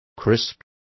Complete with pronunciation of the translation of crisp.